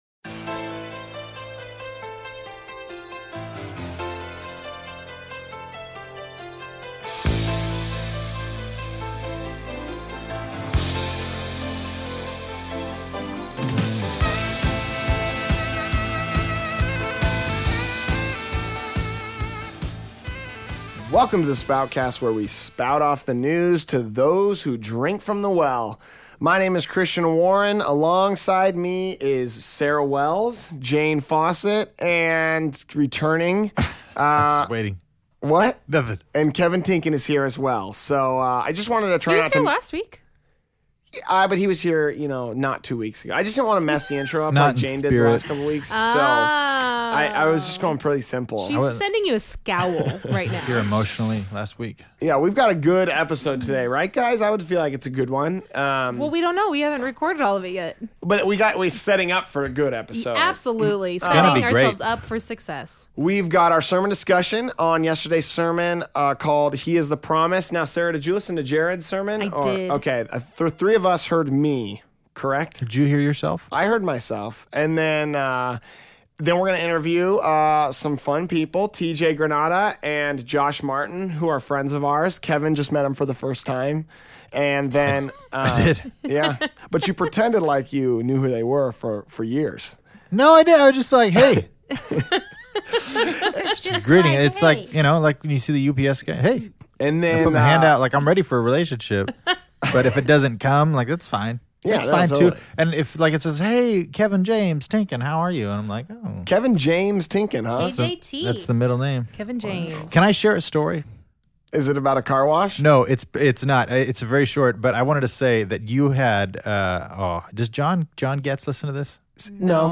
This week's music is comprised of bands from San Diego.